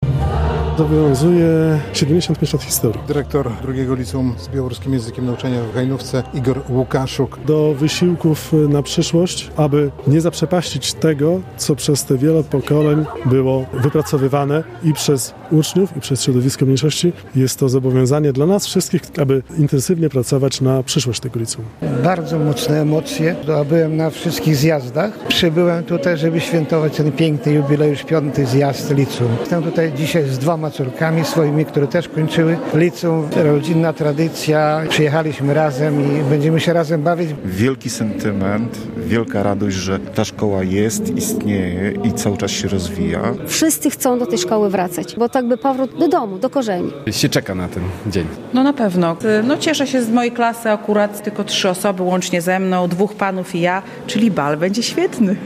Zjazd absolwentów II LO w Hajnówce - relacja